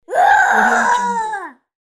Toddler Boy Lion Roar Botão de Som